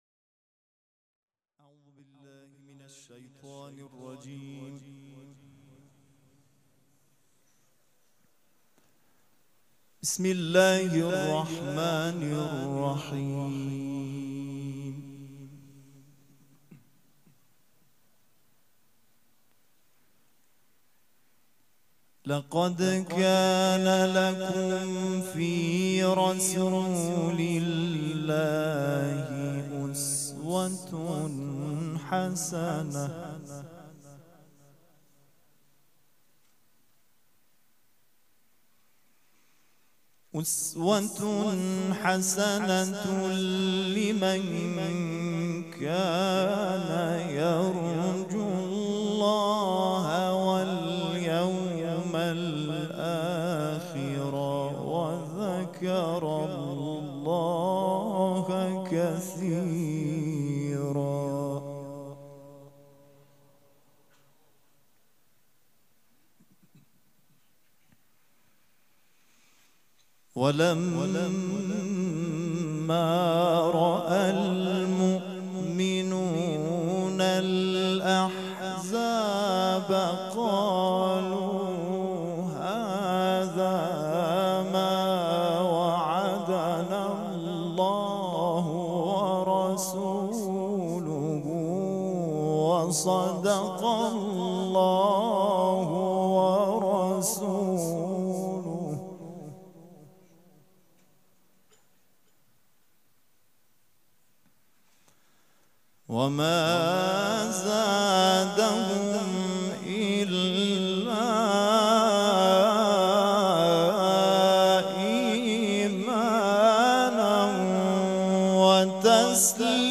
قرائت قرآن کریم
مراسم عزاداری شب هشتم